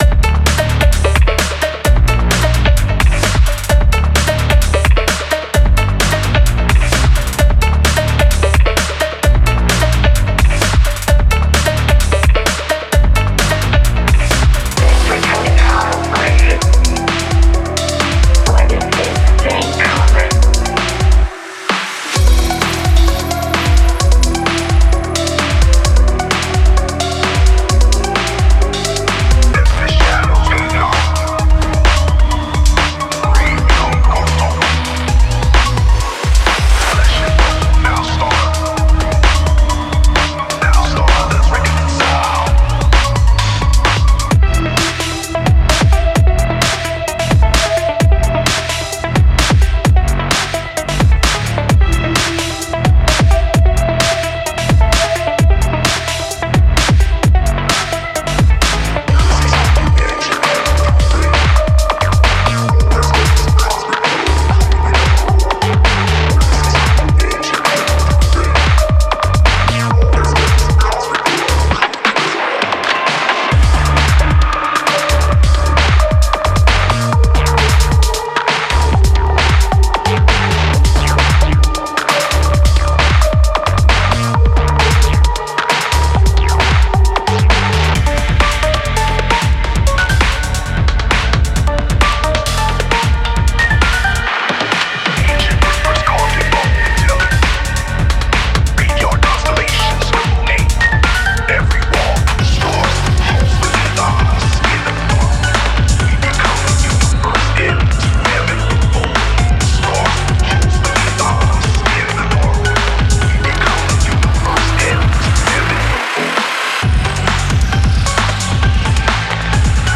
Genre:Electro
これらのサウンドは、機械と有機体、深海と宇宙の境界を曖昧にするようデザインされています。
デモサウンドはコチラ↓
21 Vocoder Vocal Loops